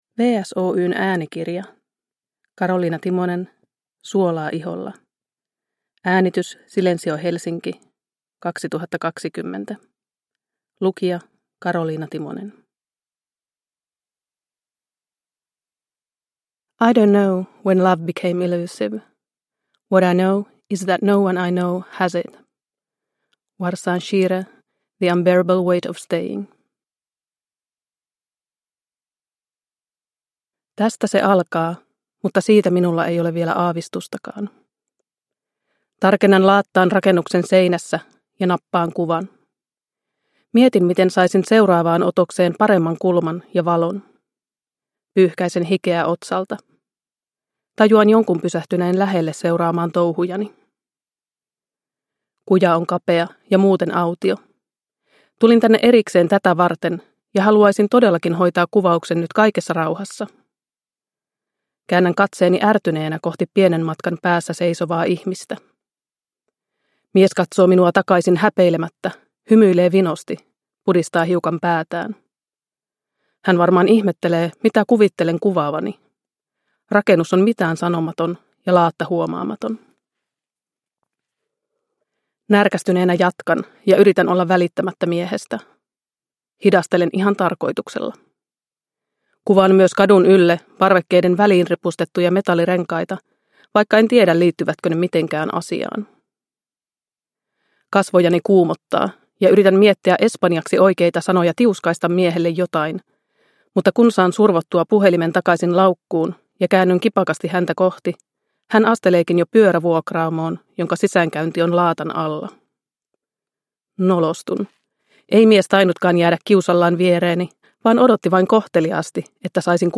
Suolaa iholla – Ljudbok – Laddas ner